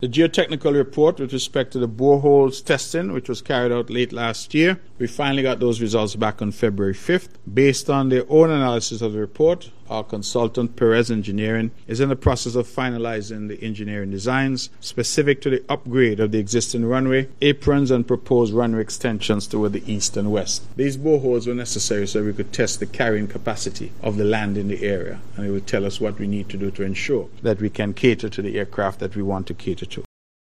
Premier of Nevis, the Hon. Mark Brantley, in late February provided an update on the Vance W. Amory International Airport development project, reporting steady progress on the technical and planning phases: